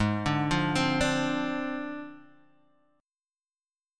Ab6b5 Chord